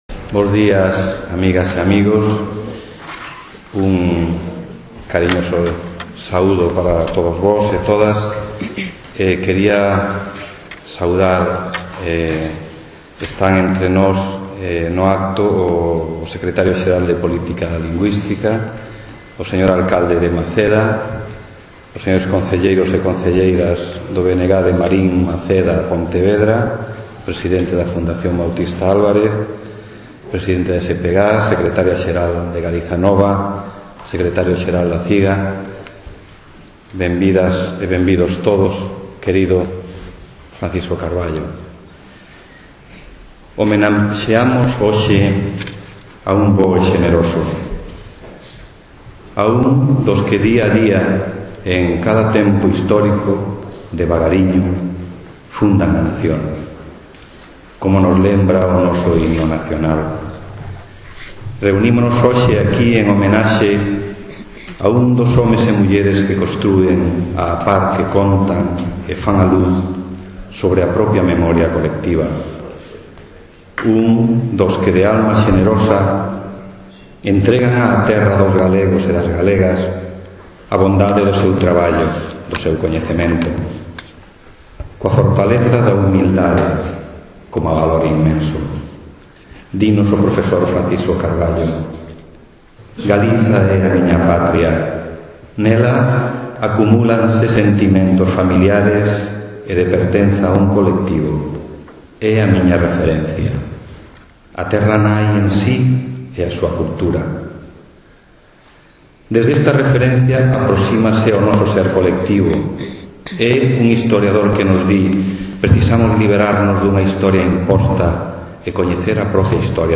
3 de marzo de 2012 - \u00C1s 12.00 no sal\u00F3n de actos do Museo do Pobo Galego.\r\nCoa colaboraci\u00F3n de CEDRO.
Entrega dunha placa conmemorativa ao homenaxeado.